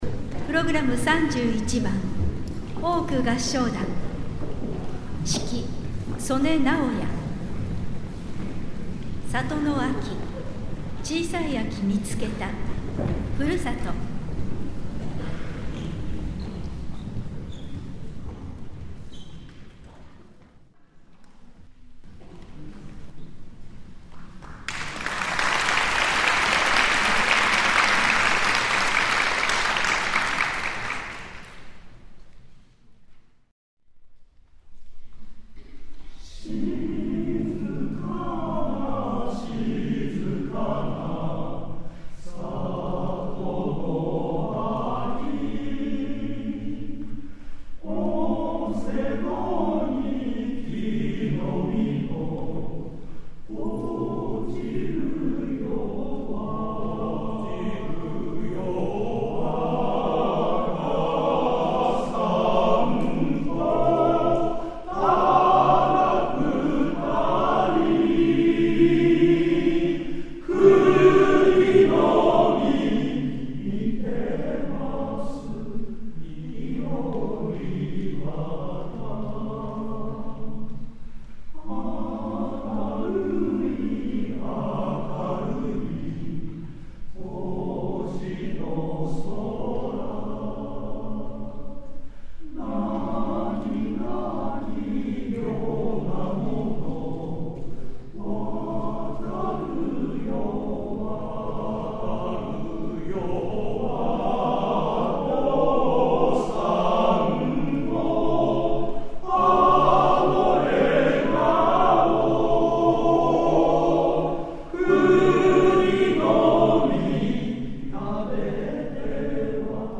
世田谷合唱祭
会場 世田谷区民会館